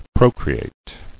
[pro·cre·ate]